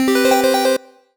collect_item_chime_03.wav